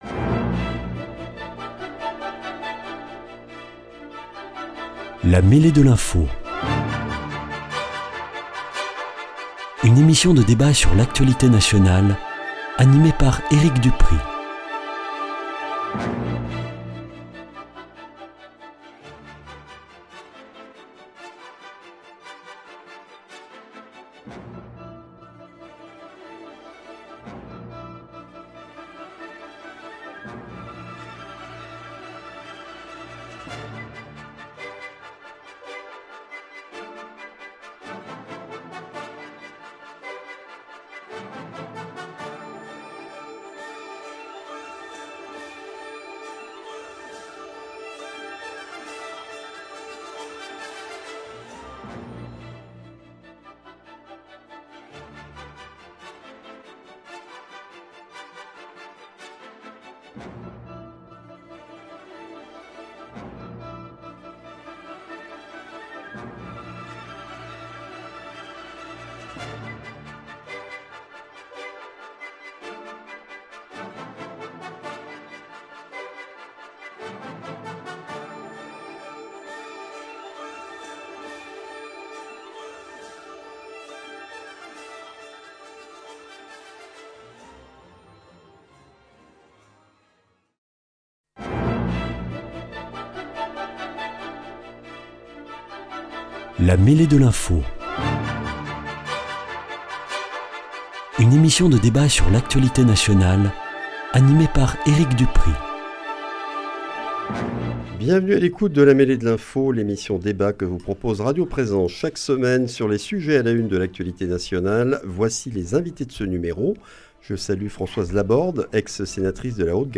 Invités : Françoise LABORDE, ex-sénatrice de la Haute-Garonne, membre du RDSE